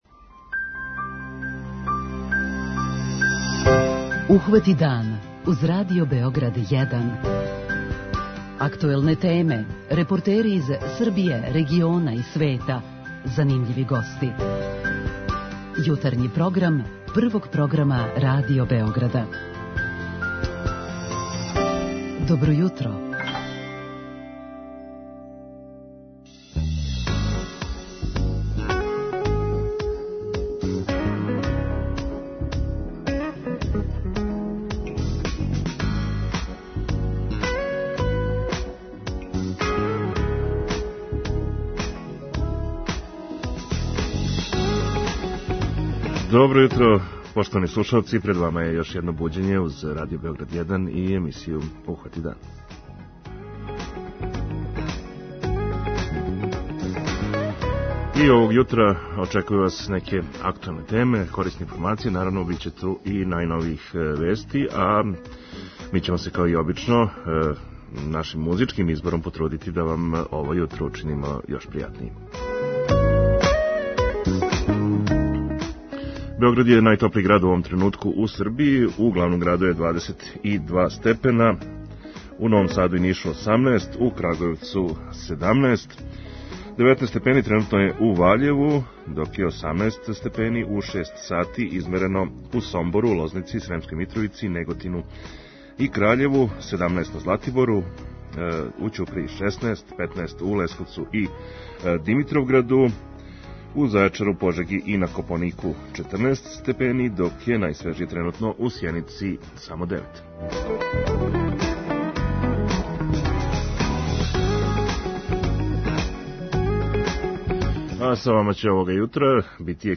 Јутарњи програм Радио Београда 1!